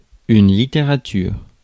Ääntäminen
Ääntäminen France: IPA: /li.te.ʁa.tyʁ/ Haettu sana löytyi näillä lähdekielillä: ranska Käännös Substantiivit 1. литература {f} (literatura) 2. книжнина Suku: f .